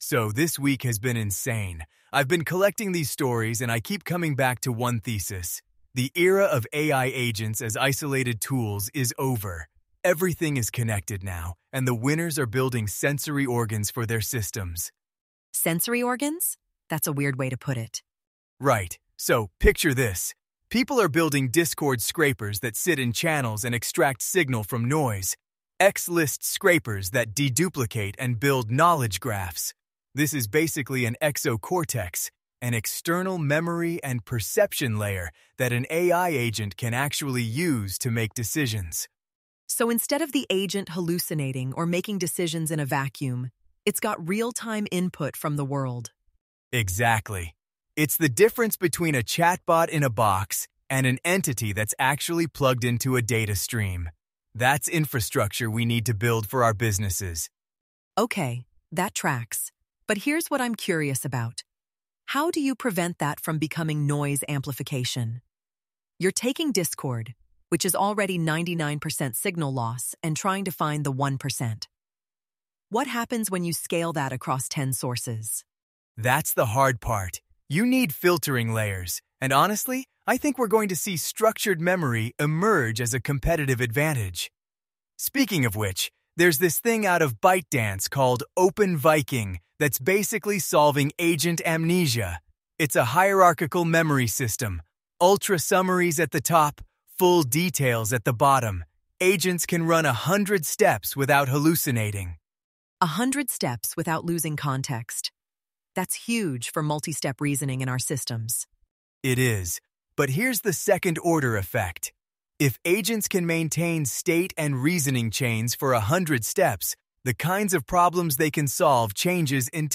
Two-voice AI discussion from the daily digest.